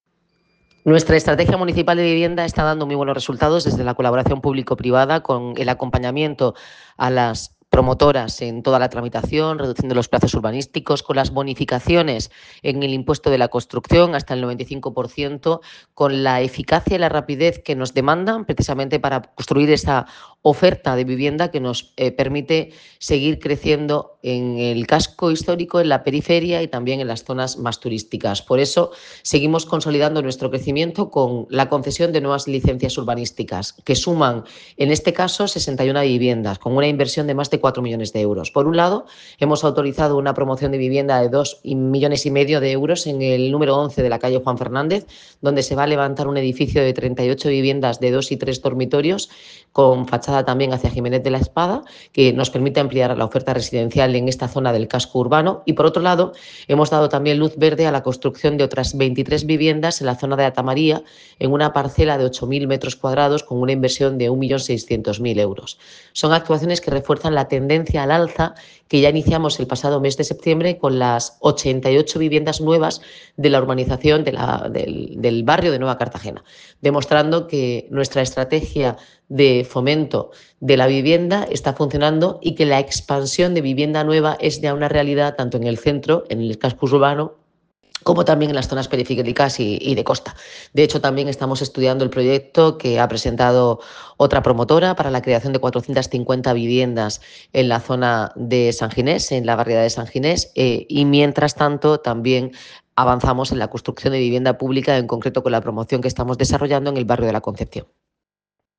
Declaraciones de la alcaldesa Noelia Arroyo.